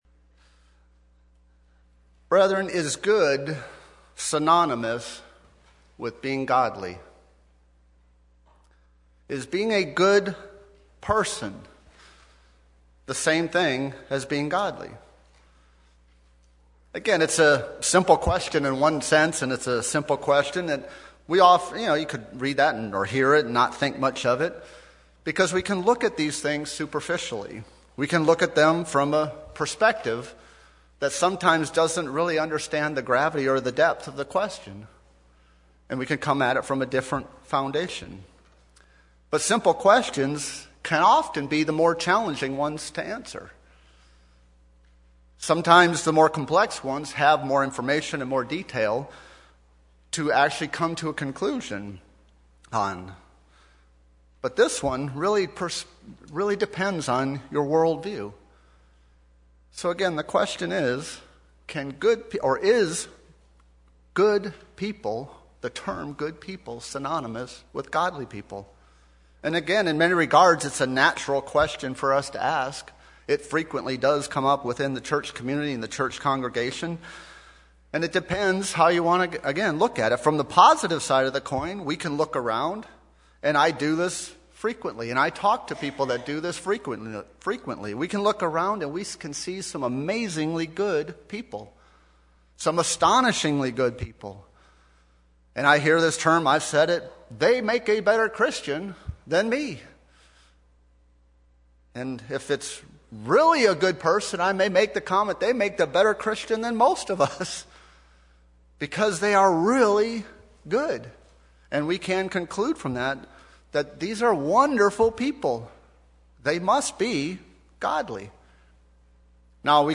Sermons
Given in Nashville, TN